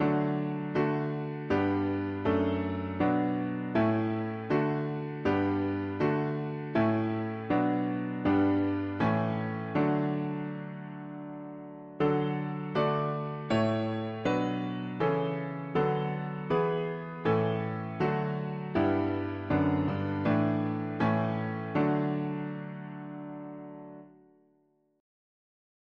Tune: DUNDEE Music: The CL.
4part